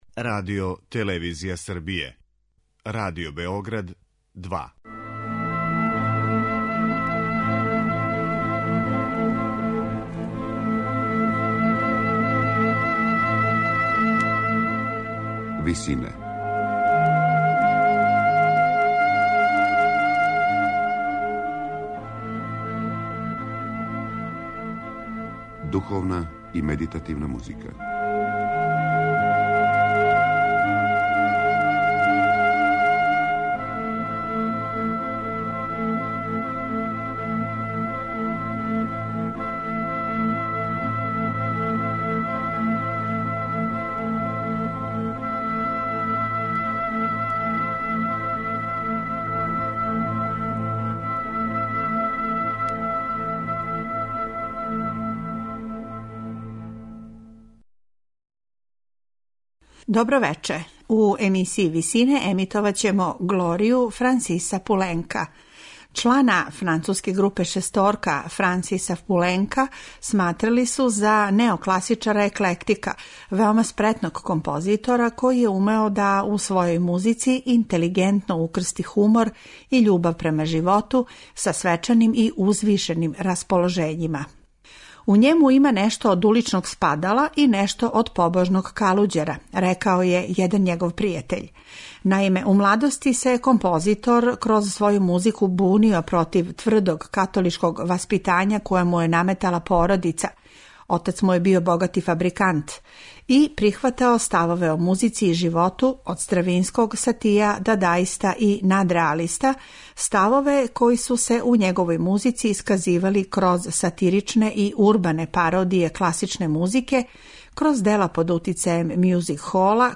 Духовне композиције Френсиса Пуленкa